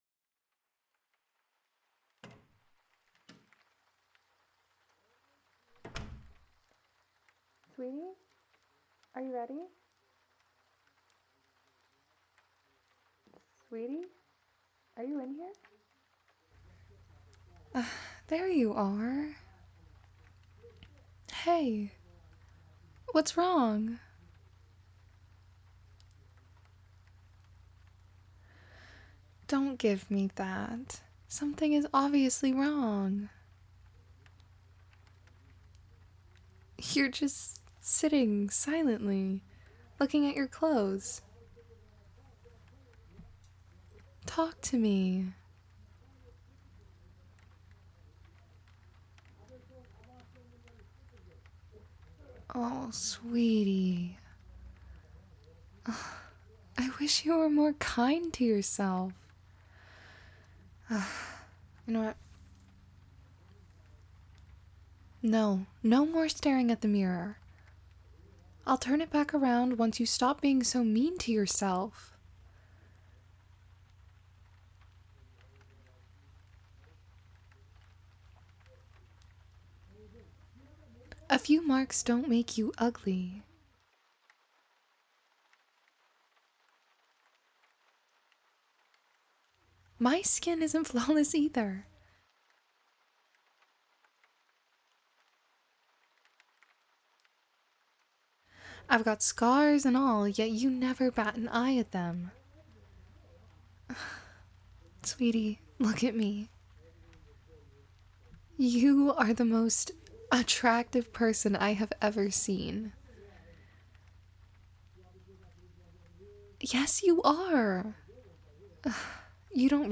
F4A